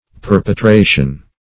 Perpetration \Per`pe*tra"tion\, n. [L. perpetratio: cf. F.